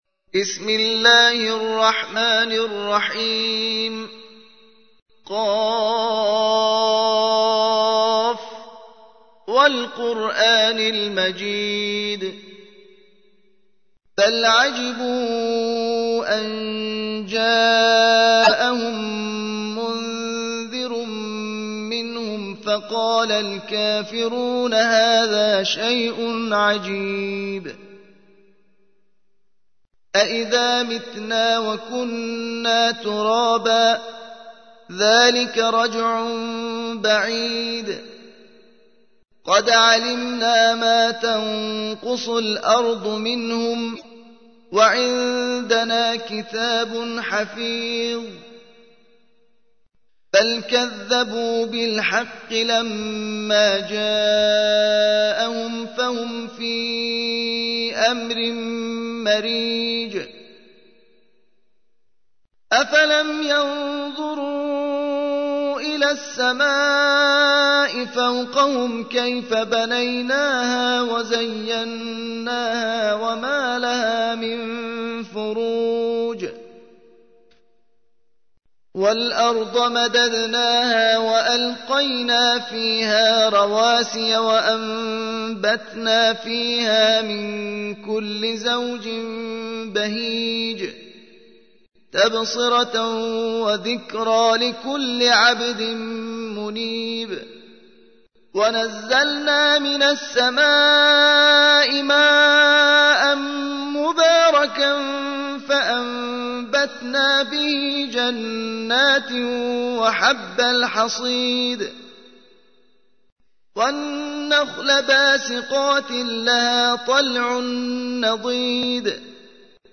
50. سورة ق / القارئ